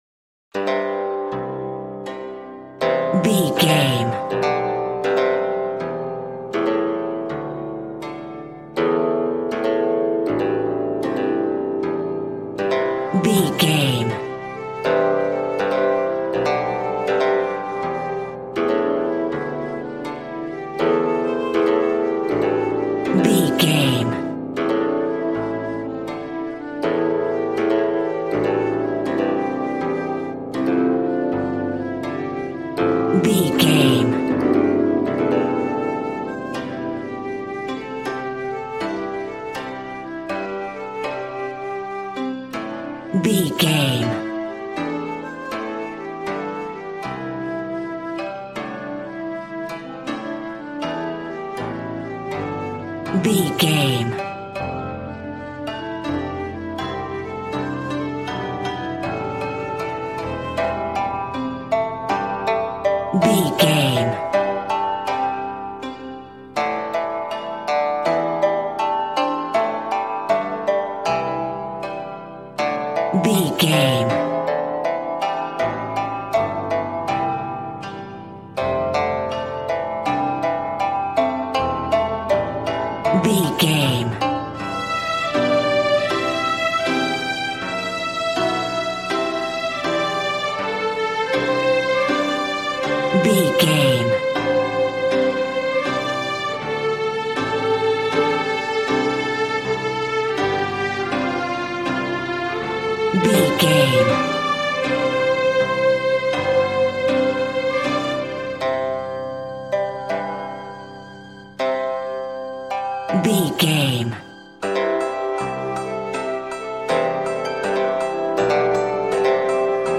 Aeolian/Minor
happy
bouncy
conga